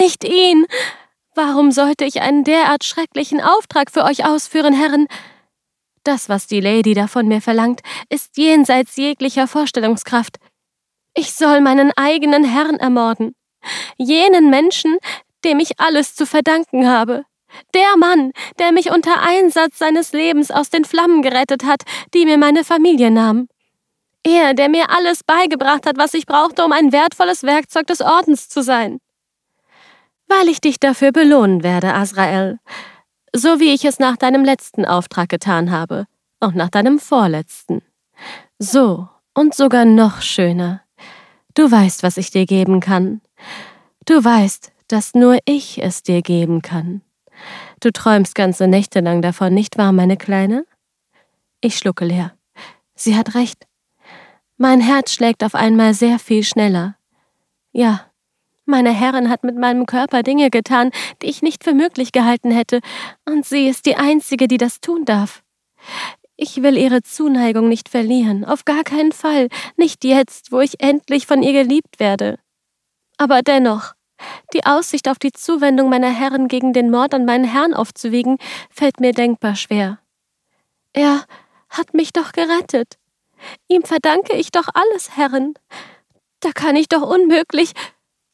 Livres audio
Home Studio professionnel.
Microphone : Shure SM7B
HauteMezzo-sopranoSoprano